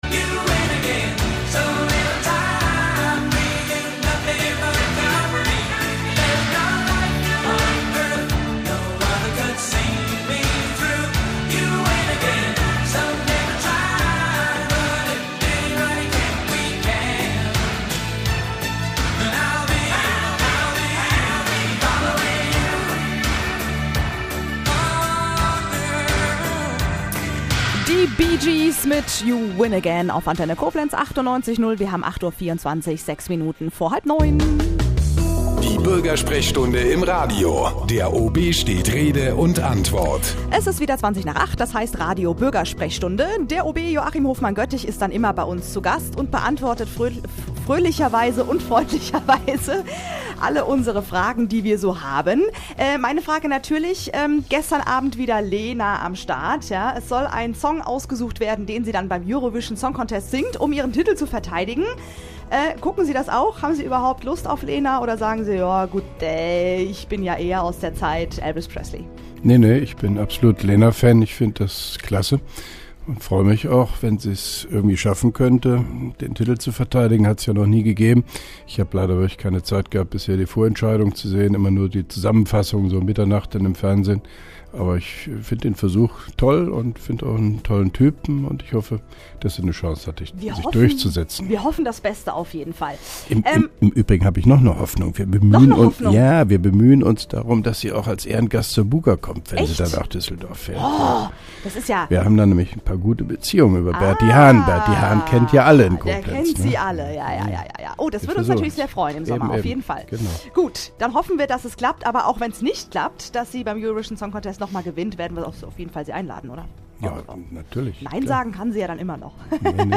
(1) Koblenzer Radio-Bürgersprechstunde mit OB Hofmann-Göttig 08.02.2011